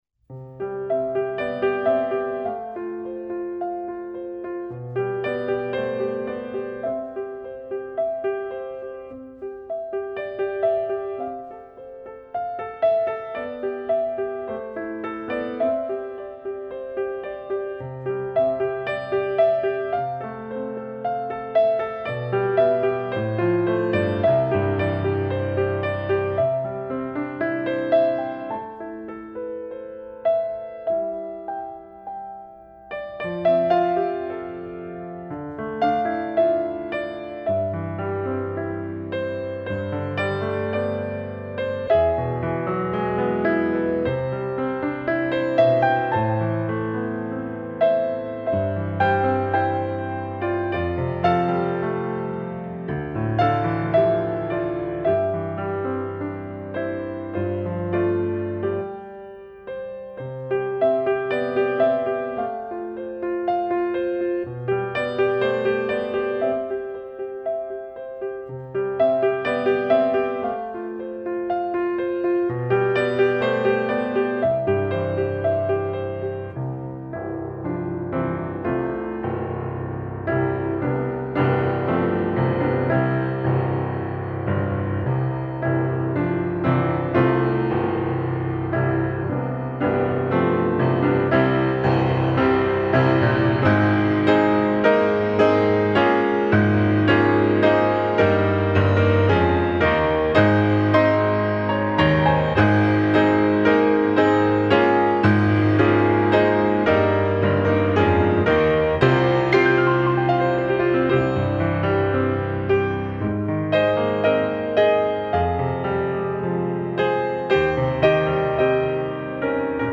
with an Irish flare
for piano. This setting is for the intermediate player.
Piano solo